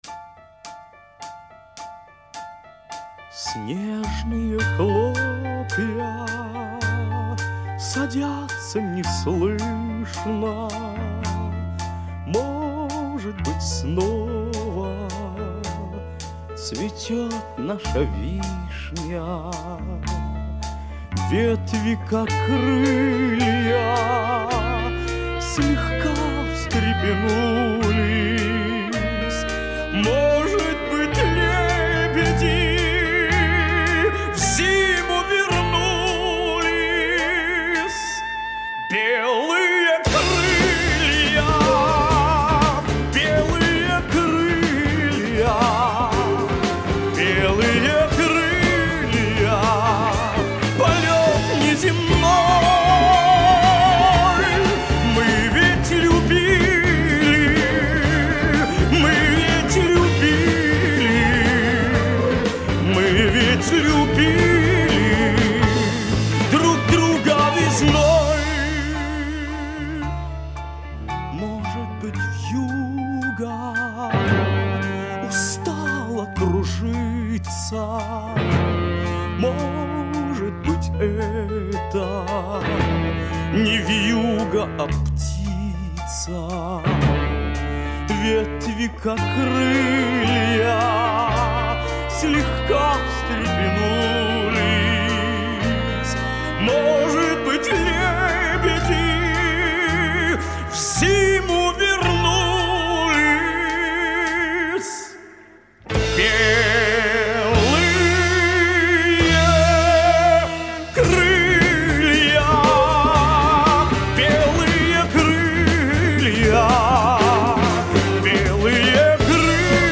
Жанр: эстрада